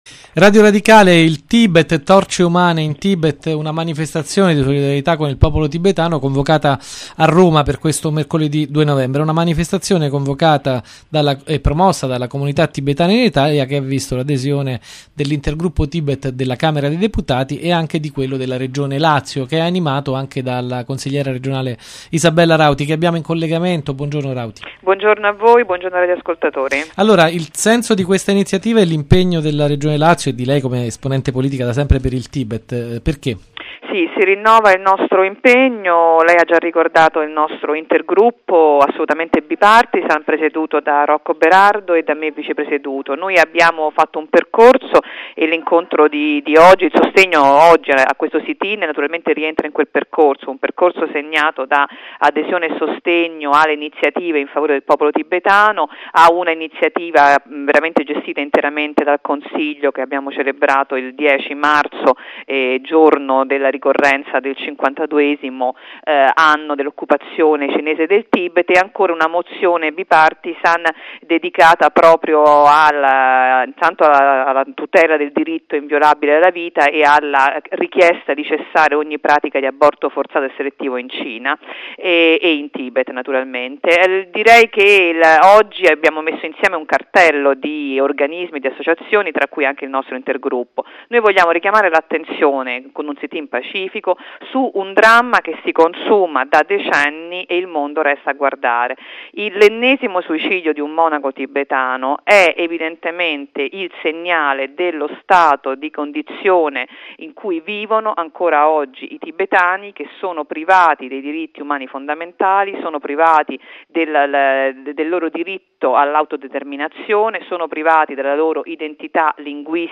Radio Radicale – Intervista a Isabella Rauti